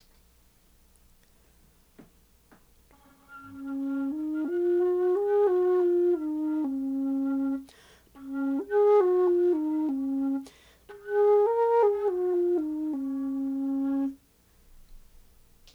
The second set of files are played on a Baroque flute made by the Von Huene Workshop, and is a copy of an instrument by A. Grenser.
Baroque Flute by Von Huene Workshop
Click here to download a windows audio file of a scale being played on a baroque flute.
baroque_flute_scale.wav